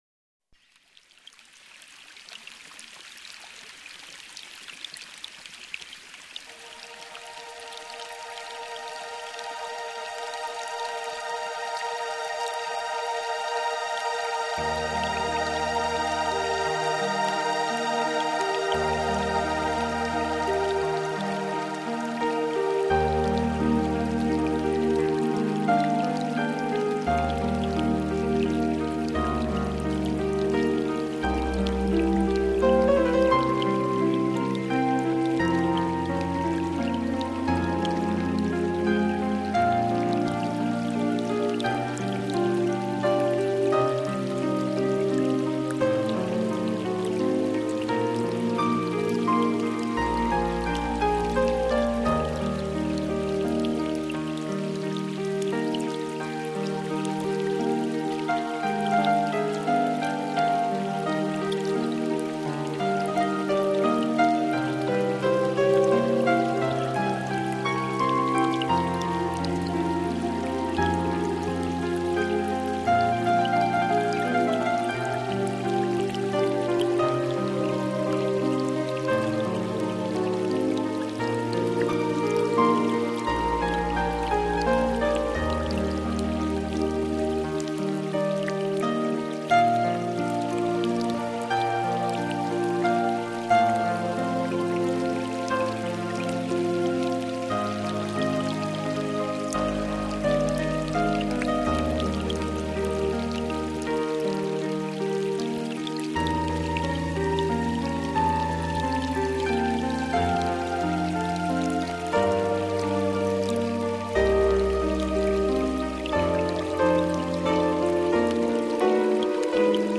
音乐类型：新世纪音乐(NEW AGE)